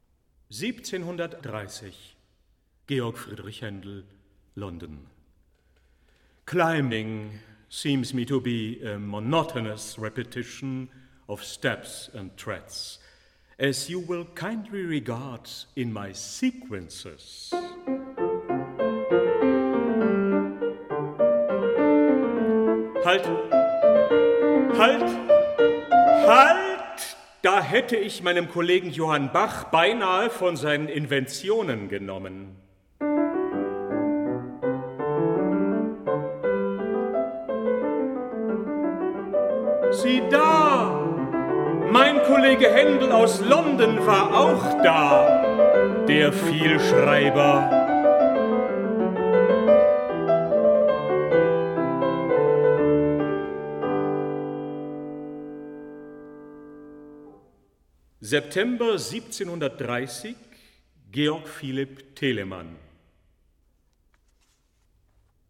piano/Klavier